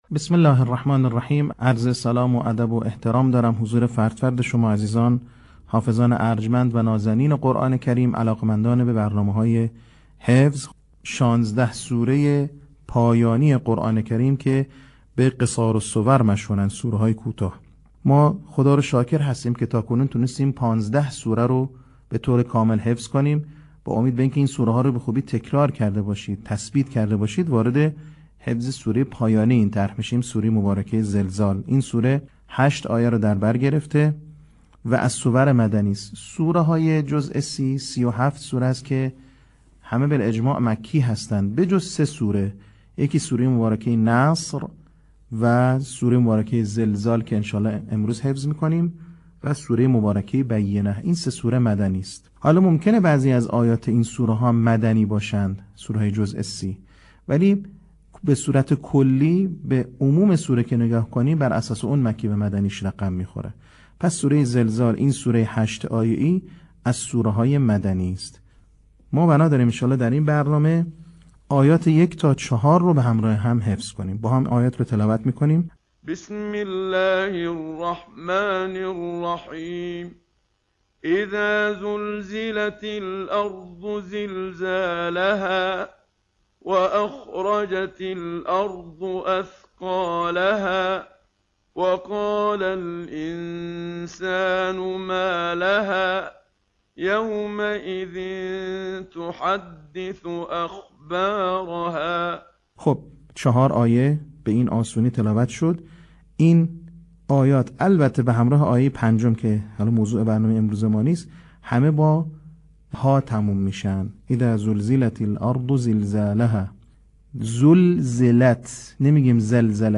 صوت | آموزش حفظ سوره زلزال